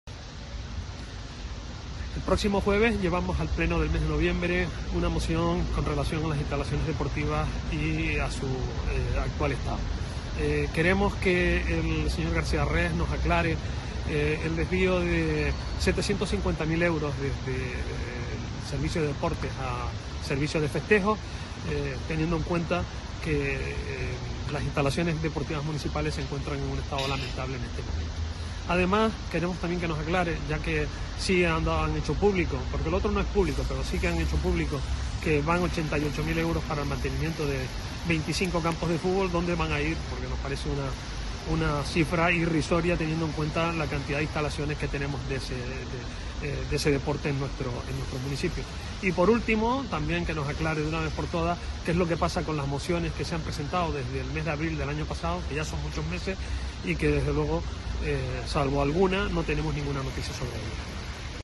Según ha denunciado en una rueda de prensa el concejal Felipe Coello, ese desvío se produjo hace 25 días entre las dos áreas, que forman parte de la concejalía que dirige Pedro García Rex, por lo que presentará una moción en el pleno de este jueves para pedir explicaciones al respecto.